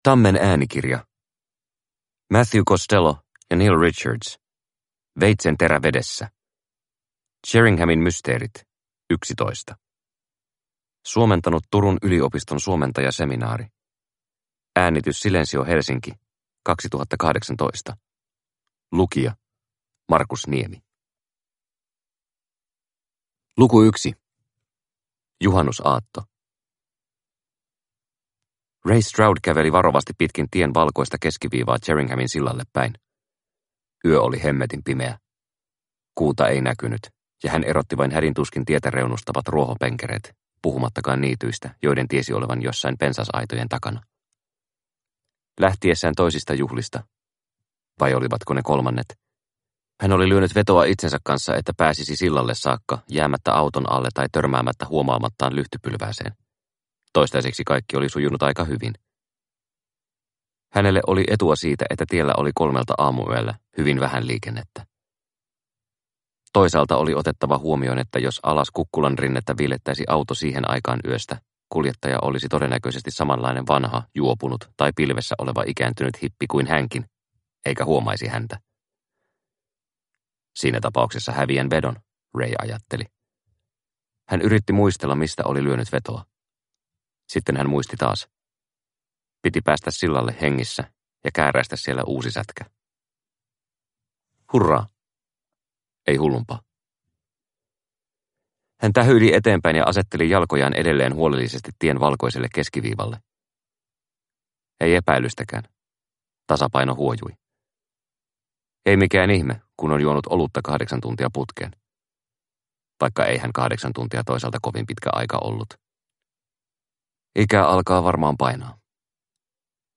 Veitsenterä vedessä – Ljudbok – Laddas ner